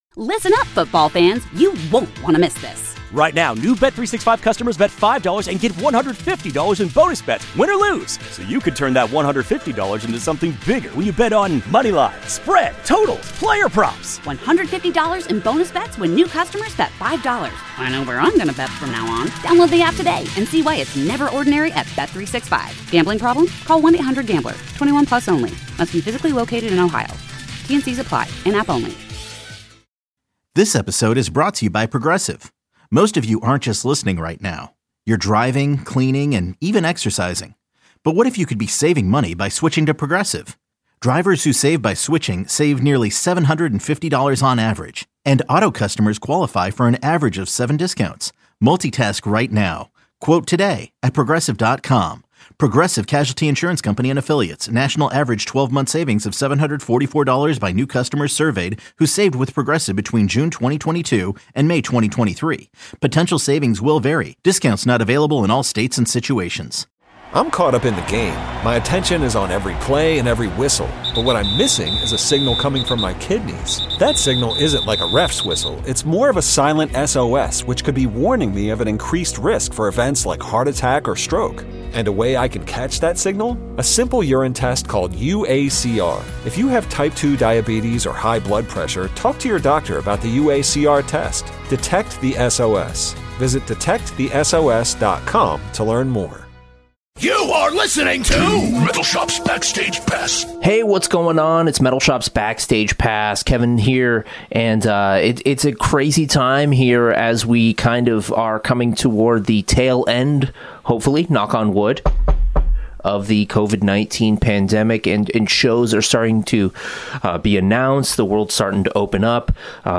Beschreibung vor 4 Jahren This is a special compilation episode featuring three special guests from very different ends of the globe. First up is an interview with the solo rock n roll musician Ricky Warwick who lives in Los Angeles but grew up in Ireland. Ricky also plays with the Black Star Riders and Thin Lizzy and has performed with Stiff Little Fingers in the past.